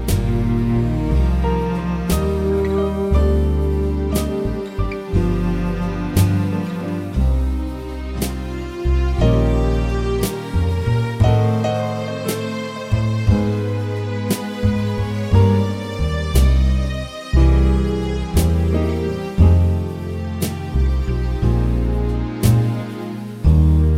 Jazz / Swing